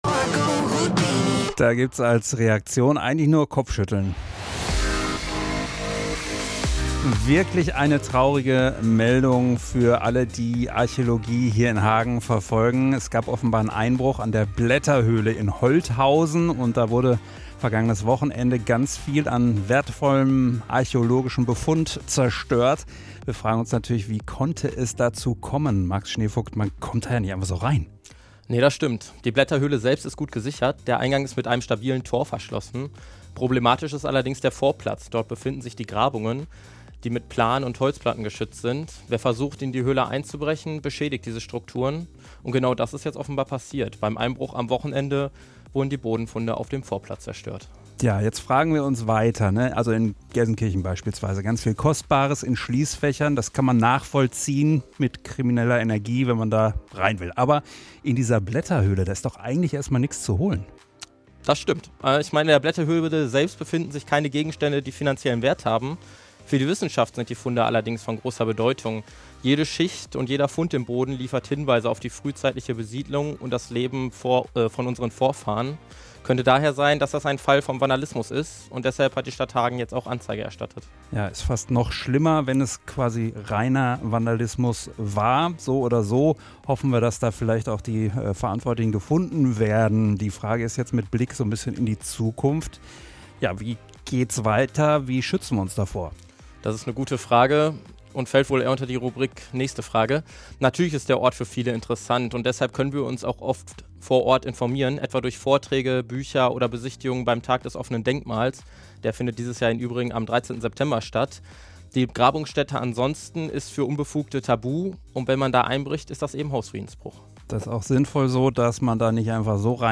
live-talk---blaetterhoehle.mp3